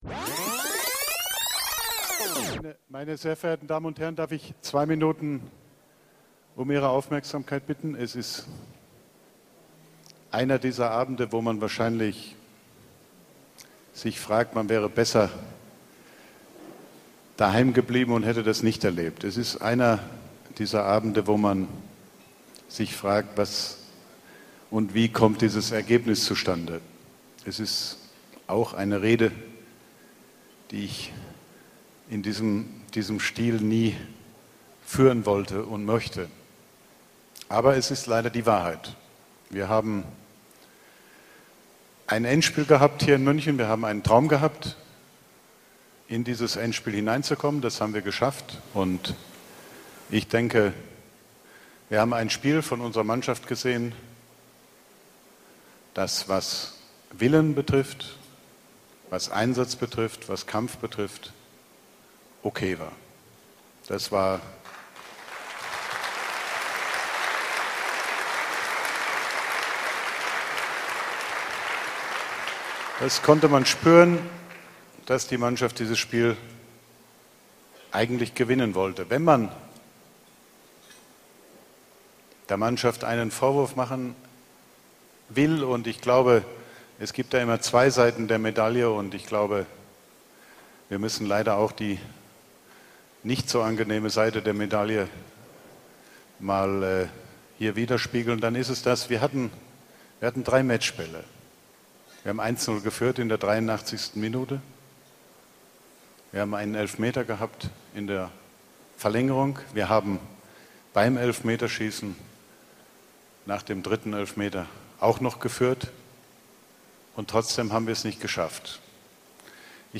Bankettrede von Karl-Heinz Rummenigge nach dem Champions-League Finale 2012 gegen FC Chelsea
Die Bankettrede von Karl-Heinz Rummenigge nach der Niederlage im Champions-League Finale (dahoam) 2012 gegen den FC Chelsea, in der er versucht, seine Enttäuschung in Worte zu packen und spricht über nicht genutzte Matchbälle, die Trauer und einem Vergleich mit der Niederlage im Finale 1999 in Barcelona.
fehlpass-Rummenigge-Bankettrede-Chelsea.mp3